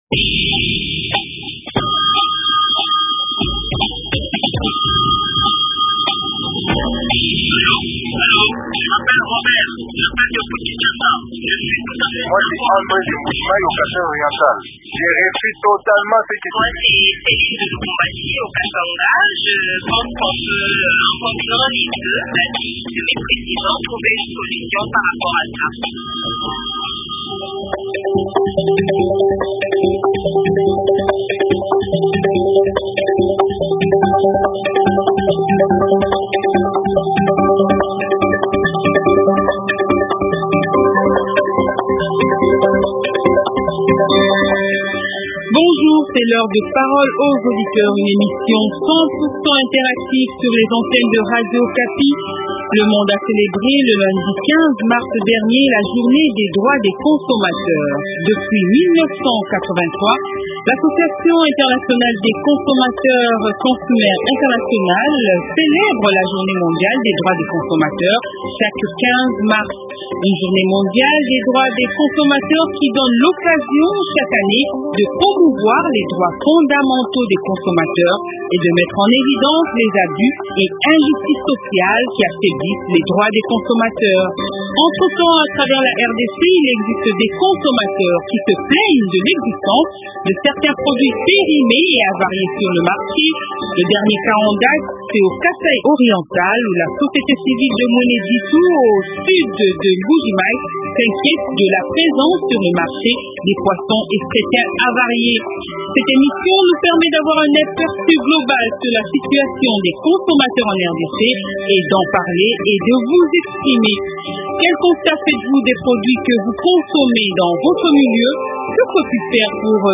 spécialiste en santé publique,chercheur en transformation et conservation des produits agro alimentaires.